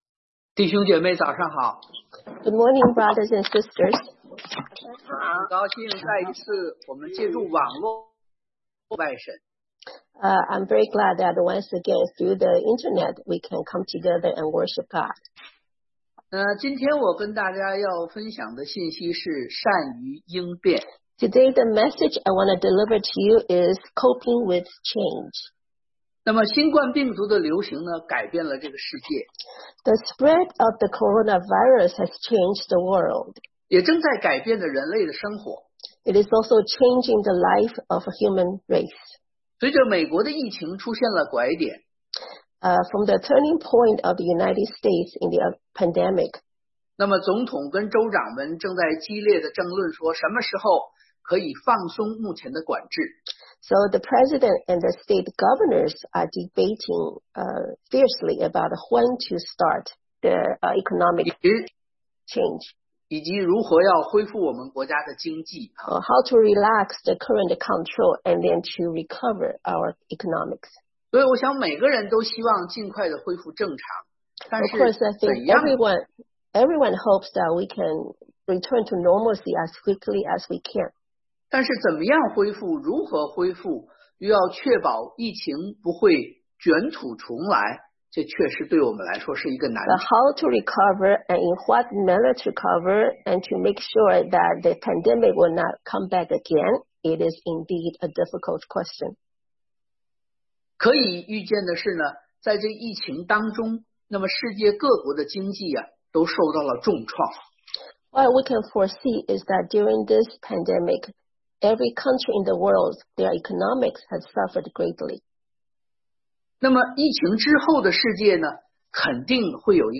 Heb. 13:8 Service Type: Sunday AM Bible Text